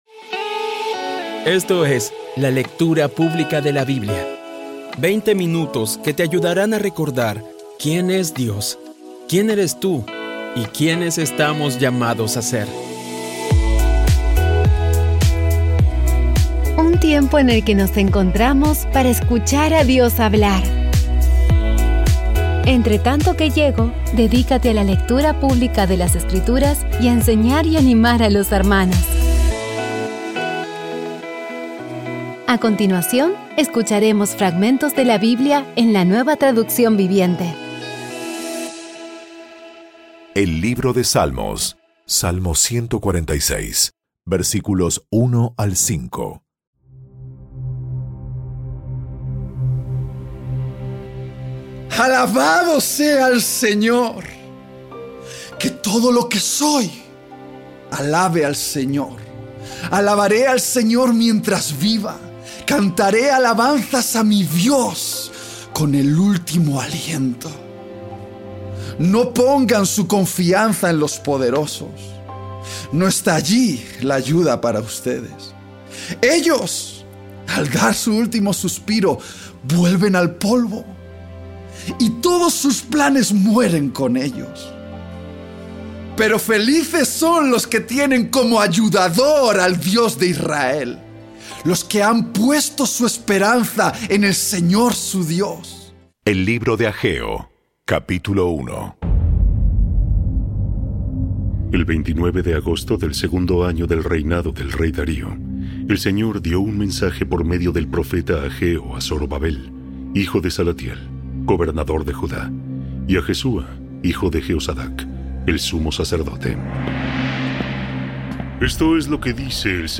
Audio Biblia Dramatizada Episodio 357
Poco a poco y con las maravillosas voces actuadas de los protagonistas vas degustando las palabras de esa guía que Dios nos dio.